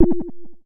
bloop.ogg